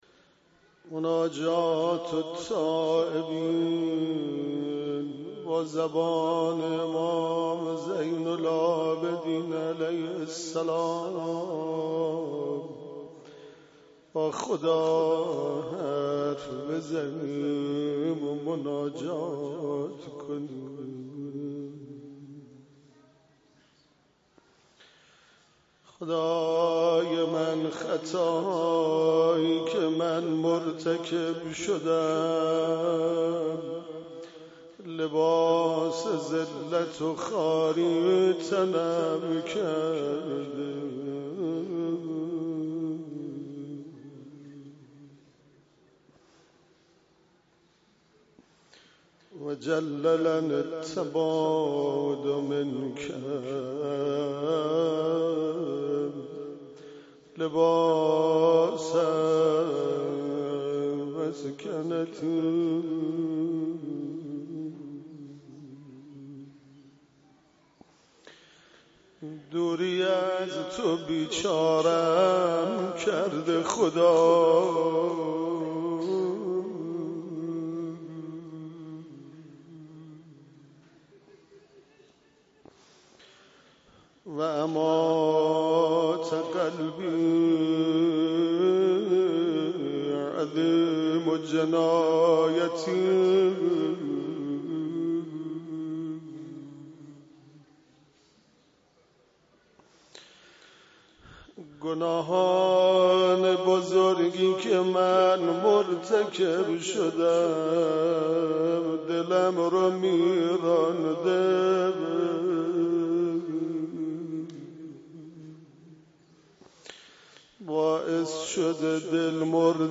مراسم شب های ماه مبارک رمضان